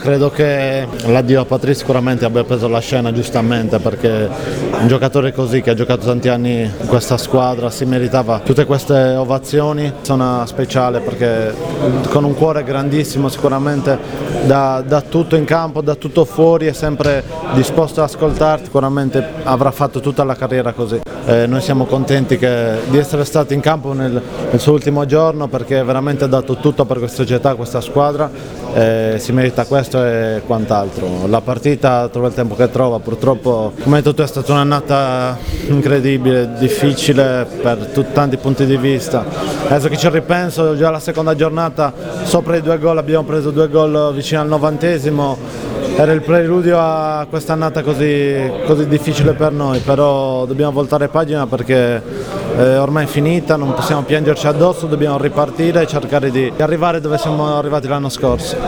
Le interviste: